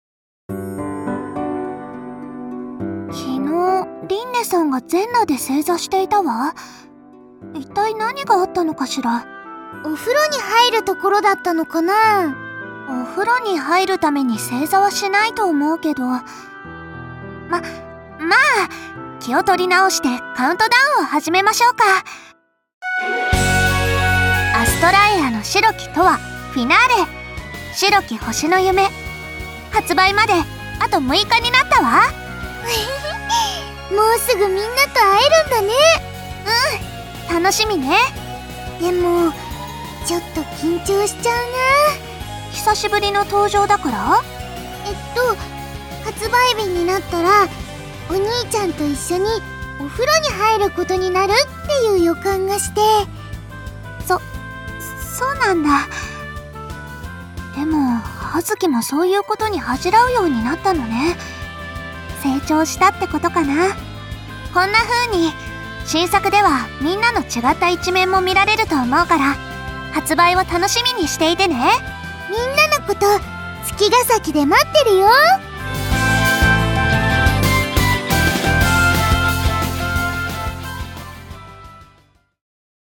『アストラエアの白き永遠 Finale』 発売6日前カウントダウンボイス（落葉＆葉月）を公開